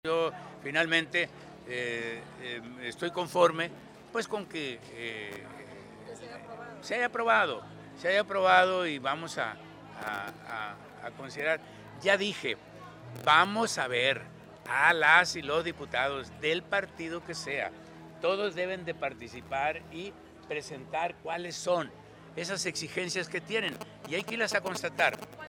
Entrevistado por los medios de comunicación luego de conocerse la aprobación de esta solicitud por parte del Pleno en su sesión de este martes, el mandatario estatal se dijo contento porque ello representa continuar con la ejecución de obra pública, pues en la propuesta de presupuesto para el 2026 se etiquetaron menos recursos para ello, alrededor de mil millones de pesos, para tener capacidad de abonar a las deudas y pasivos que dejaron las anteriores administraciones, mientras que con el crédito se financiará la obra.